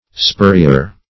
Search Result for " spurrier" : The Collaborative International Dictionary of English v.0.48: Spurrier \Spur"ri*er\ (sp[^u]r"r[i^]*[~e]r), n. One whose occupation is to make spurs.